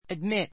admit A2 ədmít ア ド ミ ト 動詞 三単現 admits ədmíts ア ド ミ ツ 過去形・過去分詞 admitted ədmítid ア ド ミ テ ド -ing形 admitting ədmítiŋ ア ド ミ ティン ぐ ❶ （会場・会・学校などに） 入れる, 入ることを許す The boy was admitted to the school.